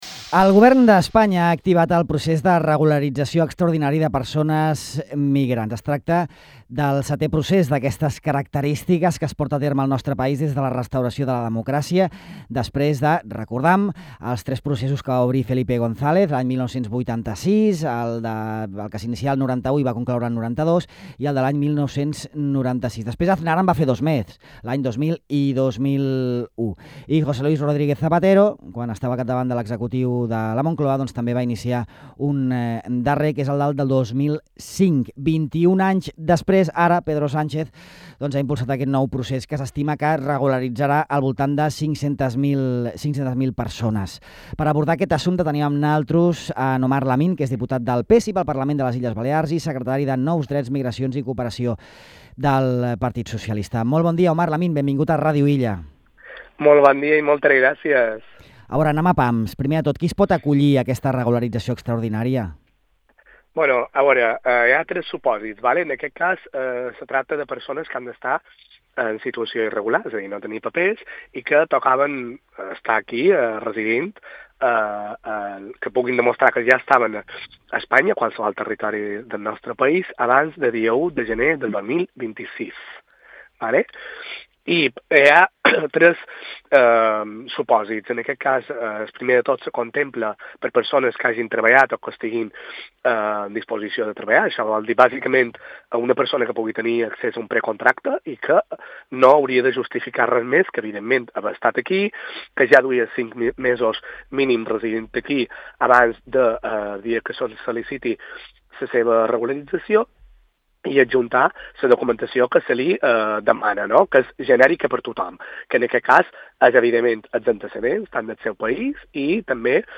El diputat del PSIB-PSOE al Parlament de les Illes Balears i secretari de Nous Drets, Migracions i Cooperació d’aquesta formació, Omar Lamín, ha explicat a Ràdio Illa els detalls del procés de regularització extraordinària de persones migrants impulsat pel Govern central, el setè d’aquestes característiques des de la restauració de la democràcia i el primer en 21 anys.